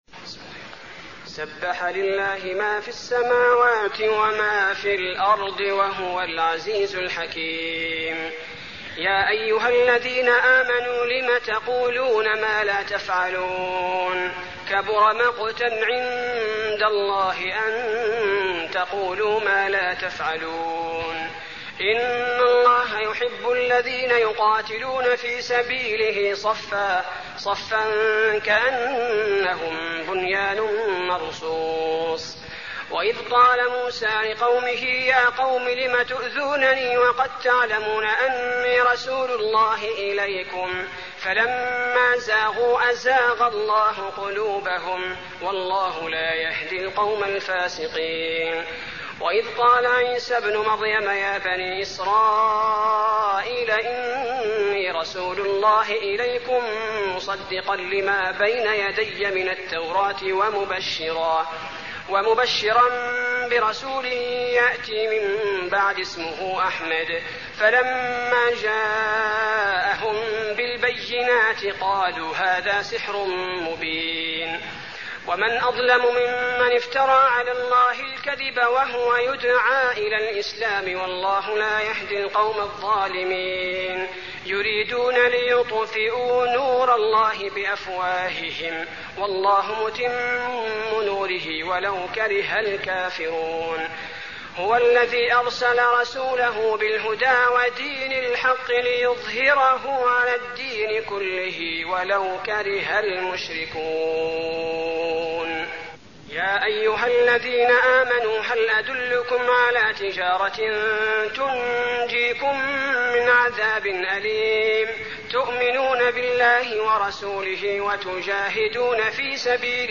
المكان: المسجد النبوي الصف